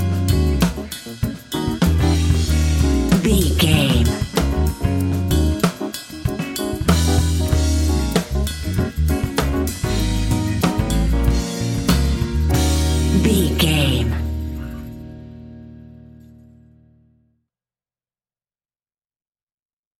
Fast paced
Uplifting
Ionian/Major
D♯